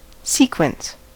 sequence: Wikimedia Commons US English Pronunciations
En-us-sequence.WAV